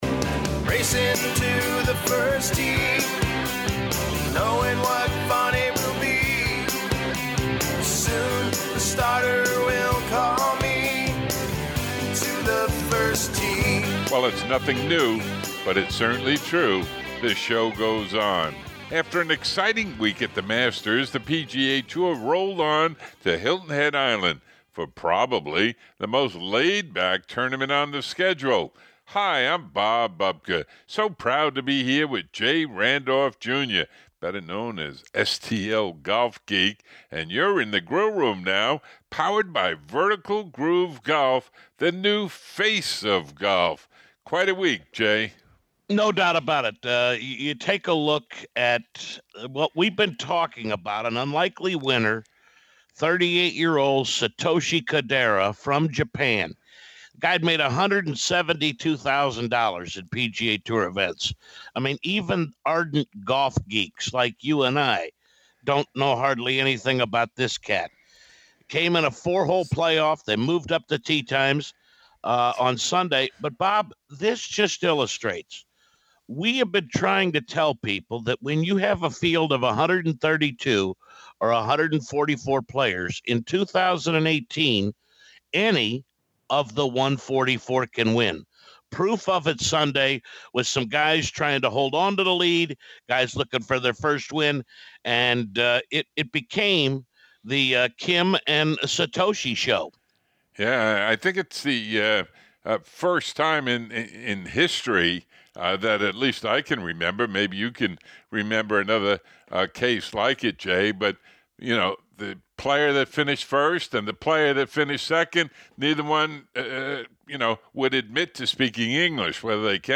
In GROOVE UP! with Vertical Groove Golf we check in on the PGA TOUR Champions and welcome John Daly and Kenny Perry back. Feature Interview